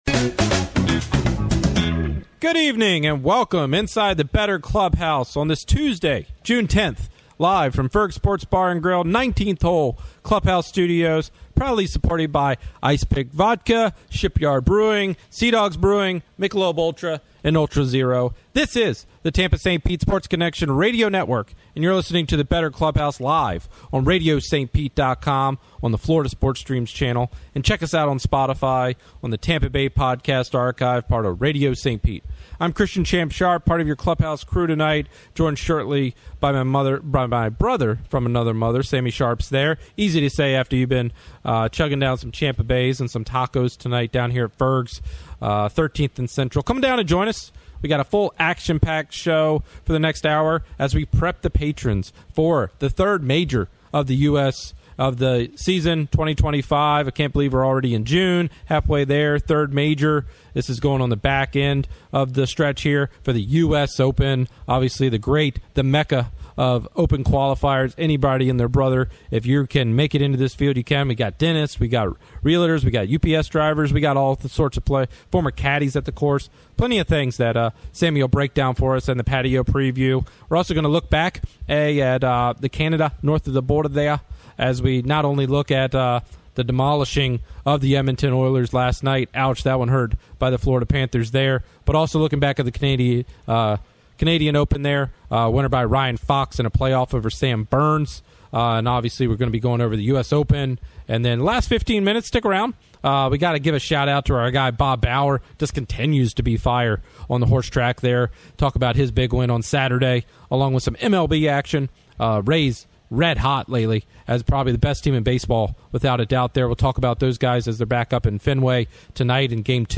"The Bettor Clubhouse" 6-10-25; Airs live from Ferg's 7pm ET Tuesdays